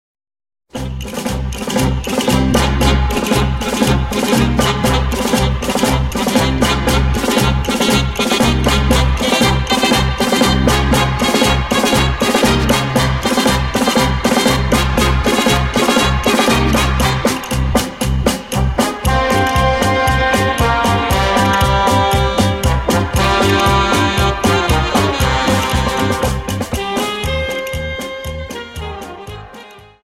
Dance: Paso Doble 59